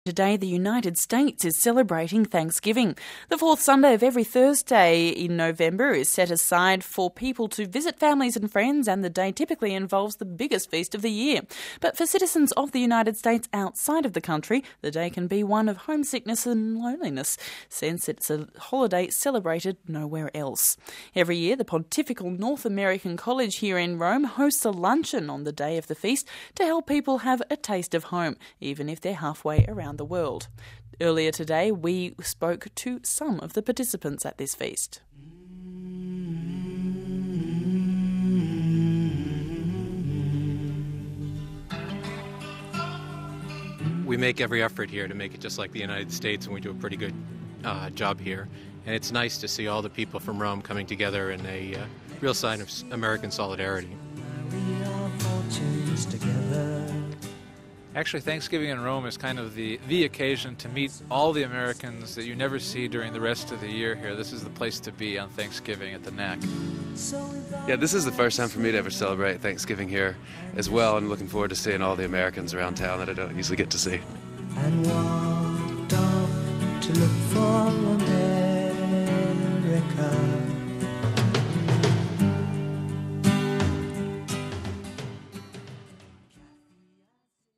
Every year the Pontifical North American College hosts a luncheon on the day of the feast to help people have a taste of home, even if they are halfway around the world.